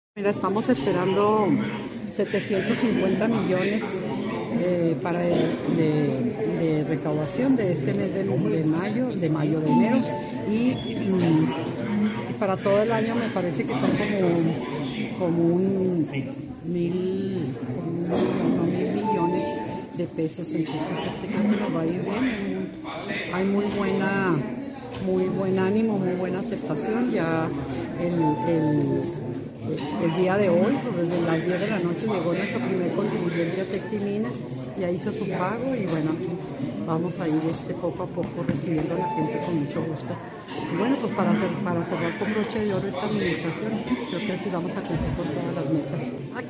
AUDIO: AMANDA CÓRDOVA, TITULAR DE LA TESORERÍA MUNICIPAL DE CHIHUAHUA
AMANDA-CORDOVA-TESORERIA.mp3